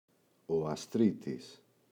αστρίτης, ο [a’stritis]: αρσενική οχιά. [άστρ(ο) -ίτης].